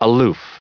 1926_aloof.ogg